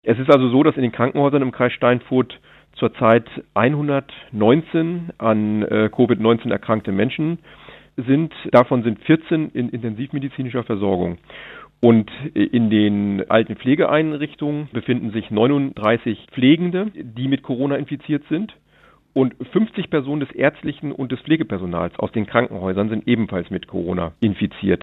Landrat Martin Sommer